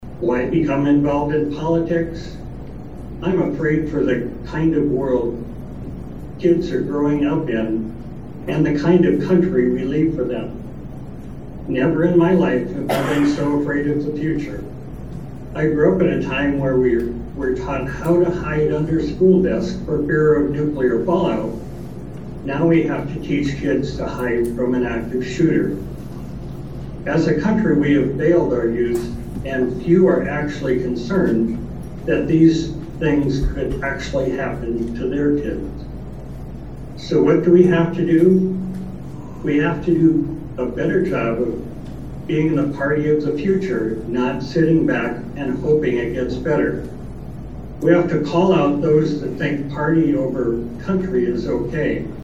At their 2022 State Convention over the weekend (July 8-9, 2022) in Fort Pierre, the South Dakota Democratic Party nominated candidates for constitutional offices, adopted its platform, adopted an amendment to the constitution and passed resolutions.
She was unable to attend the convention in person, but send a video message.